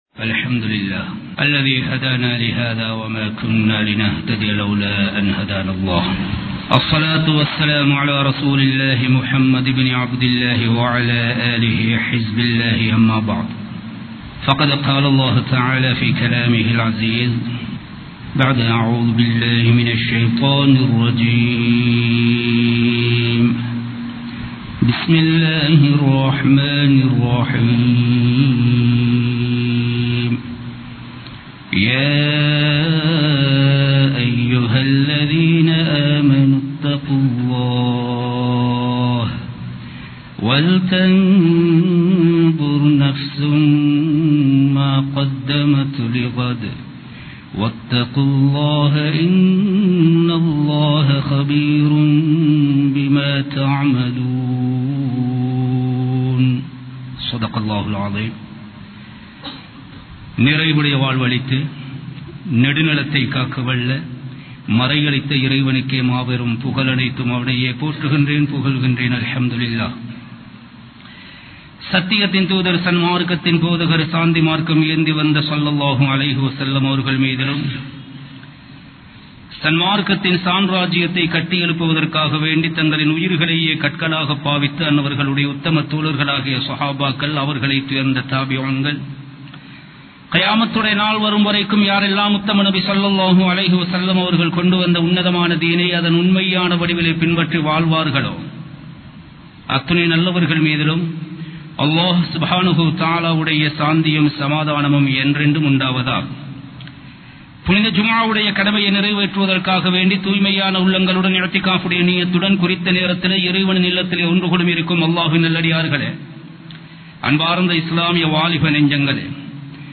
ரமழானின் முகங்கள் | Audio Bayans | All Ceylon Muslim Youth Community | Addalaichenai